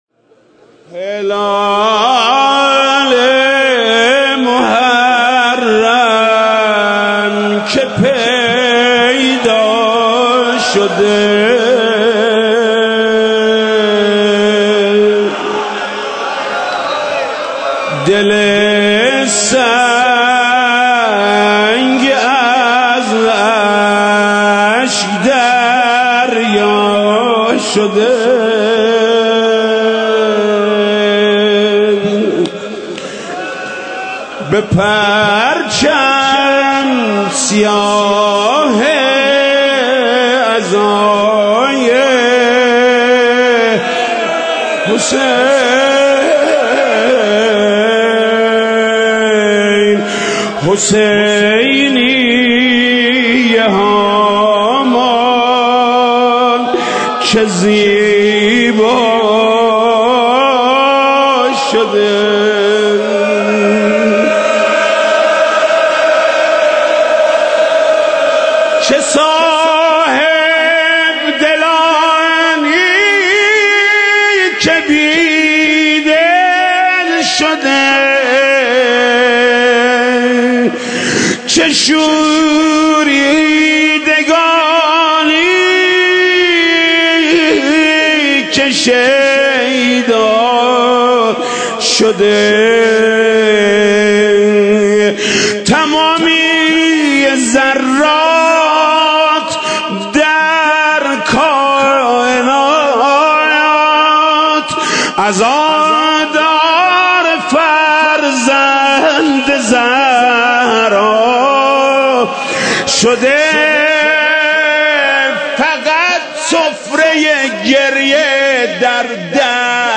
روضه شب اول محرم
روضه شب اول محرم حاج محمود کریمی هلال محرم که پیدا شده دل سنگ از اشک دریا شده تمامی ذرات در کائنات عزادار فرزند زهرا شده